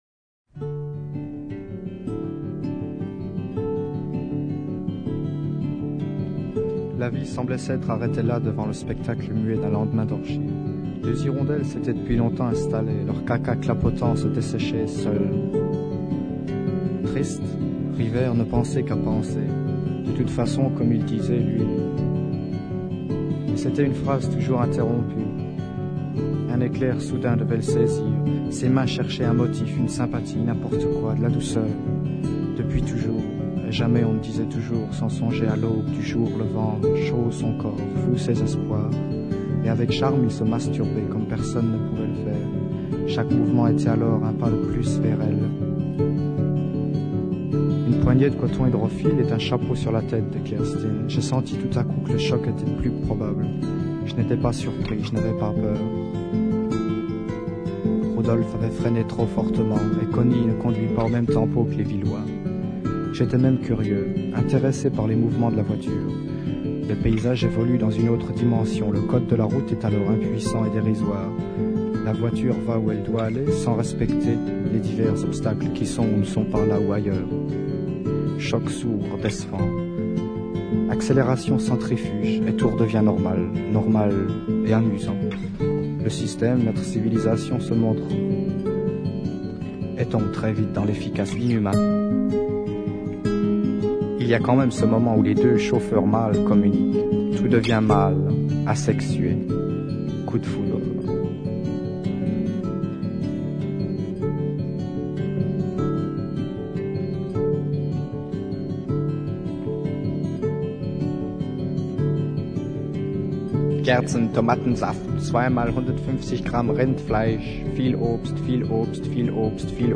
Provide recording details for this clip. Recorded: Wümme, 1971 - 1973